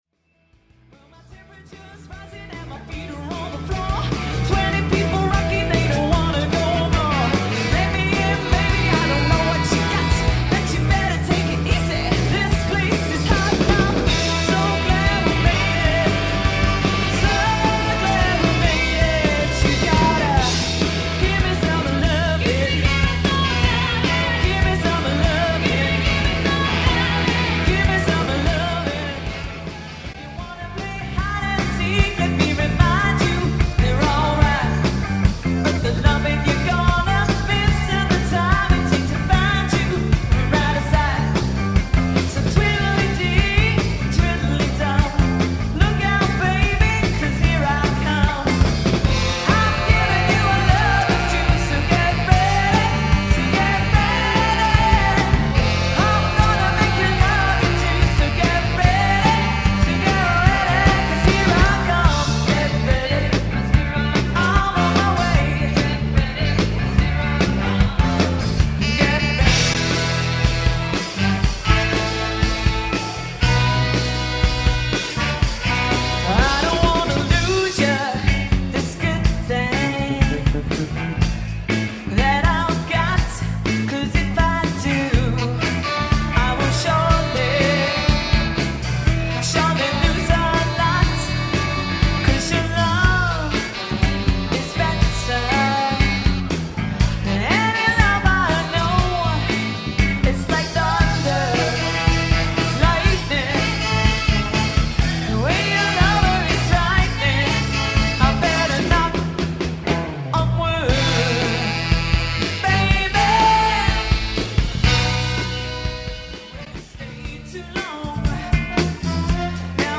cover bands
MOTOWN/SOUL
ROCK/ALTERNATIVE
POP/PARTY